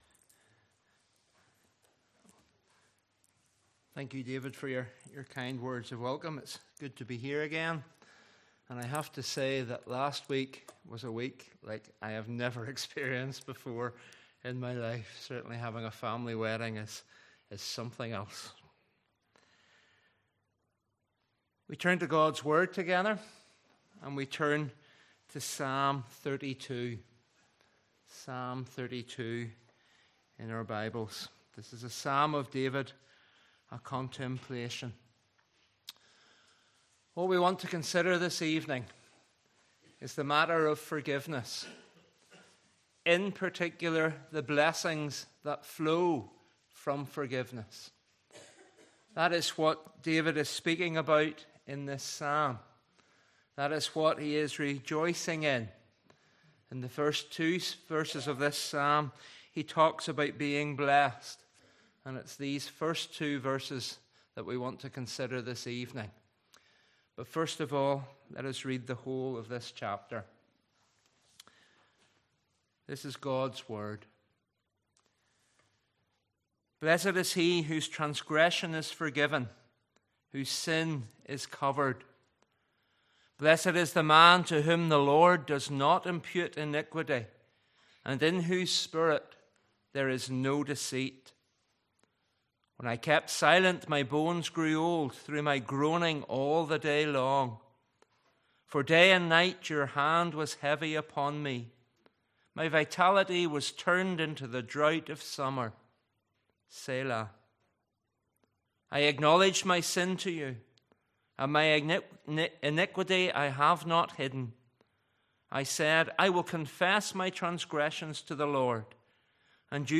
Passage: Psalms 32:1-11 Service Type: Evening Service